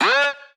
Blocka Vox.wav